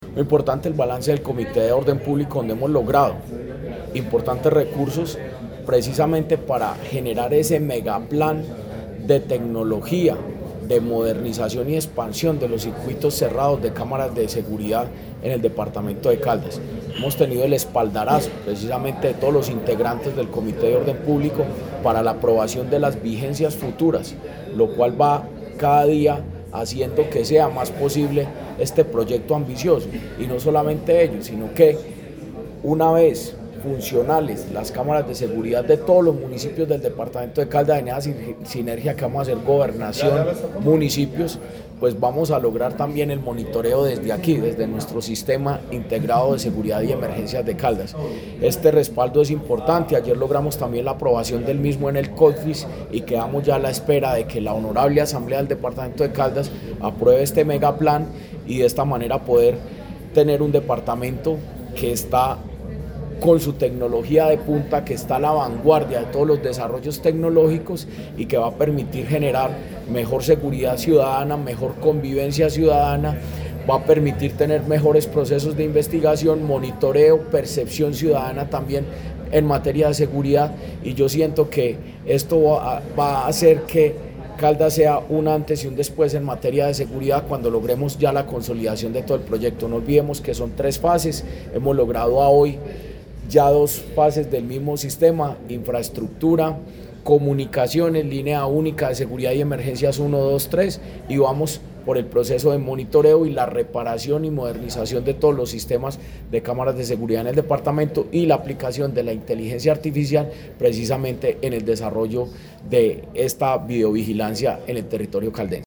En el más reciente Comité de Orden Público, el secretario de Gobierno de Caldas, Jorge Andrés Gómez Escudero, destacó los logros alcanzados en relación a la consecución de recursos que harán posible la consolidación y el desarrollo del ´megaplan de tecnología’, una apuesta que incluye la modernización y expansión de los circuitos cerrados con cámaras de seguridad en todo el territorio.
Jorge Andrés Gómez Escudero, secretario de Gobierno de Caldas